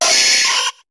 Cri de Nidorino dans Pokémon HOME.